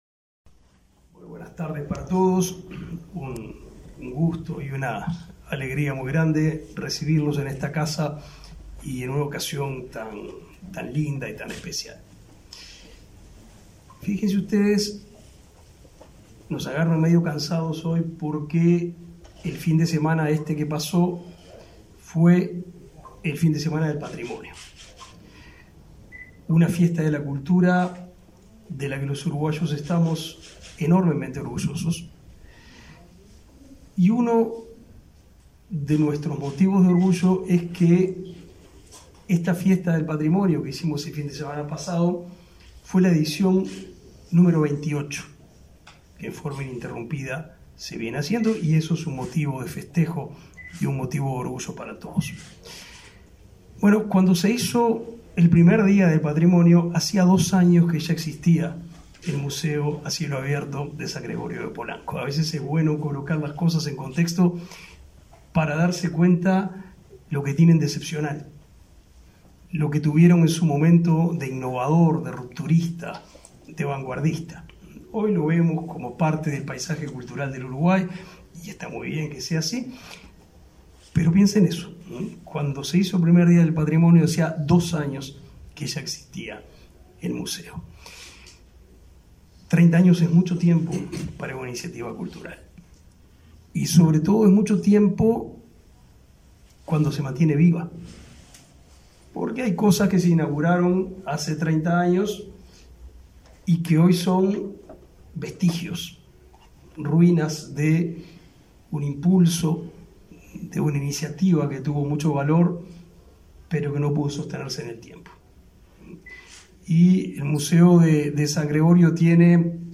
Palabras del ministro de Educación y Cultura, Pablo da Silveira
El ministro de Educación y Cultura, Pablo da Silveira, participó, este 4 de octubre, en el lanzamiento de los festejos por los 30 años del Museo